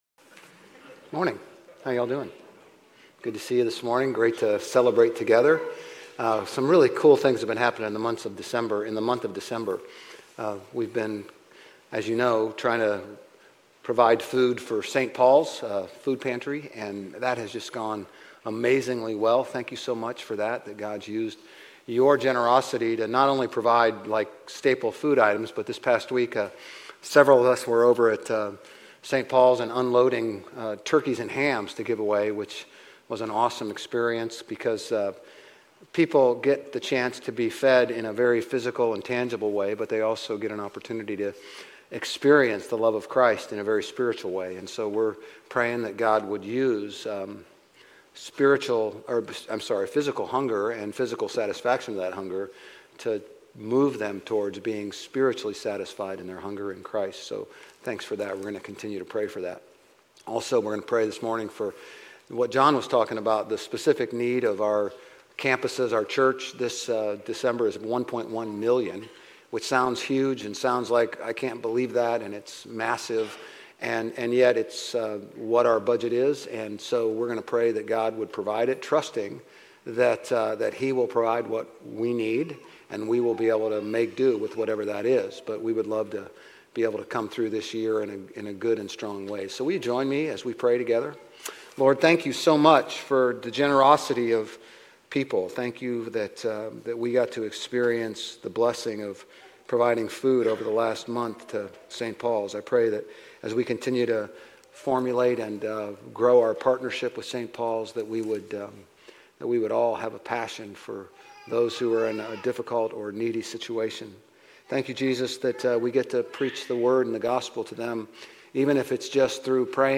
Grace Community Church Old Jacksonville Campus Sermons Advent Week 4 | LOVE Dec 22 2024 | 00:36:17 1x 00:00 / 00:36:17 Subscribe Share RSS Feed Share Link Embed